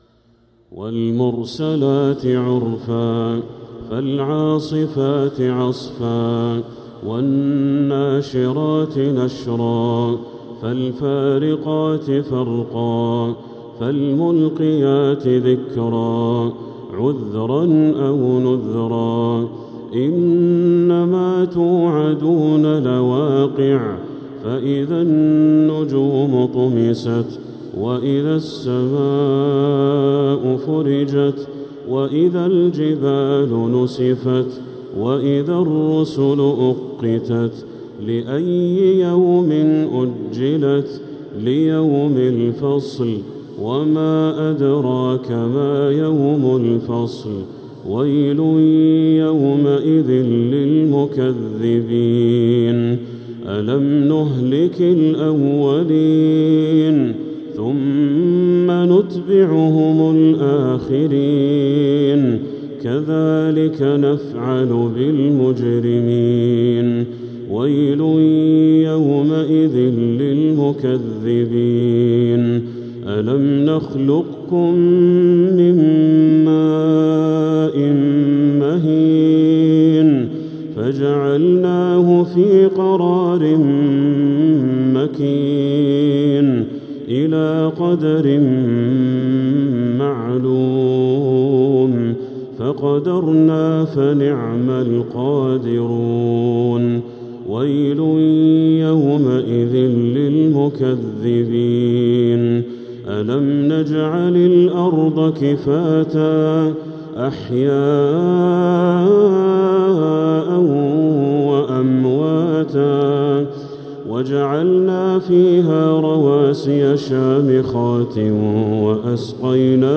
من الحرم المكي